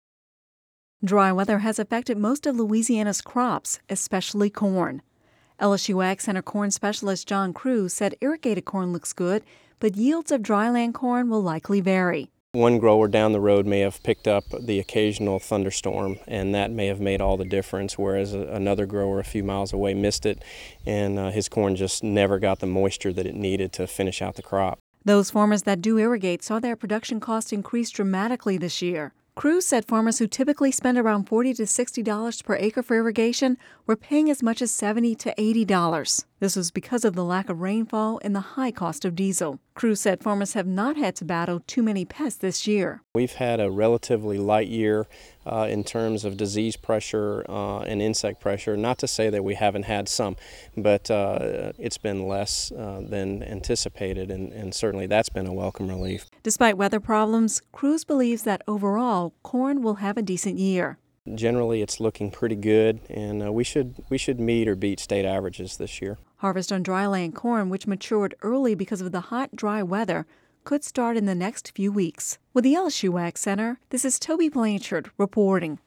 (Radio News 07/05/11) Dry weather has affected most of Louisiana’s crops, especially corn.